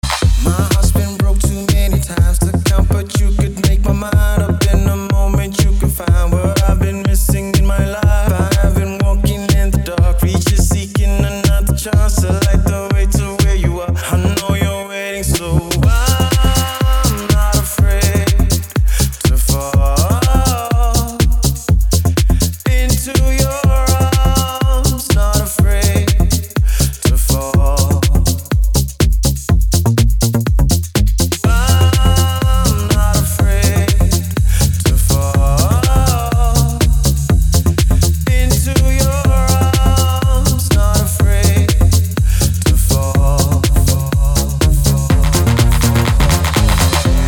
• Качество: 320, Stereo
мужской вокал
deep house
dance
Electronic
EDM
электронная музыка